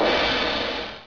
CRASHCYM.WAV